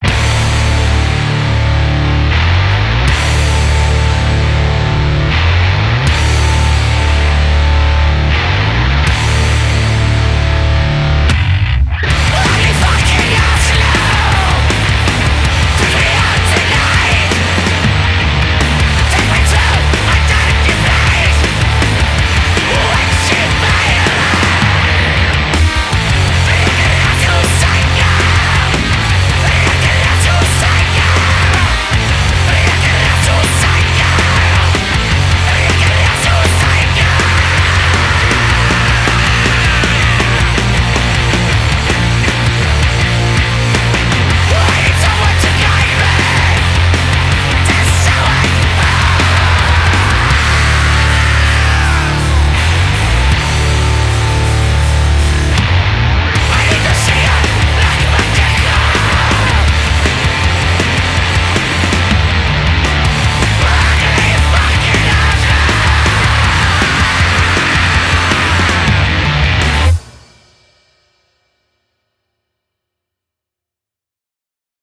Perfect tunes for bad times.